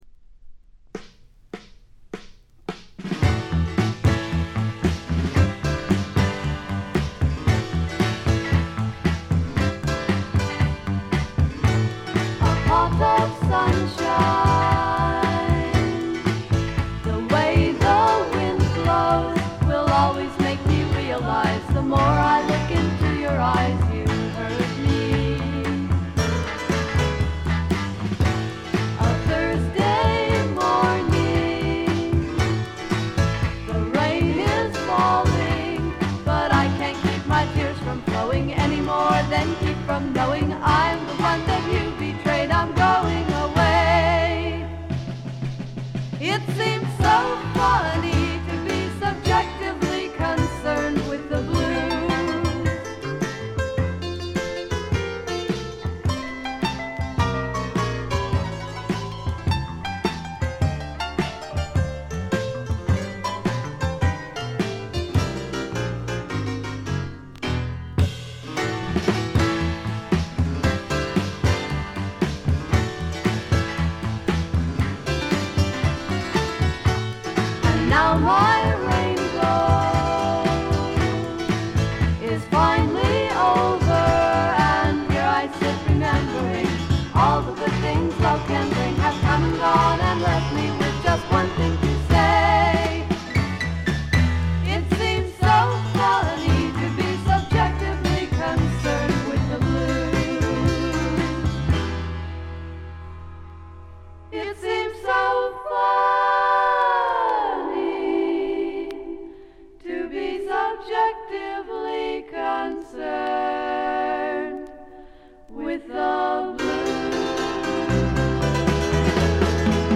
静音部での軽微なチリプチ程度。
甘酸っぱい香りが胸キュンのまばゆいばかりの青春フォークの傑作。
試聴曲は現品からの取り込み音源です。
Vocals, Guitar, Composed By ?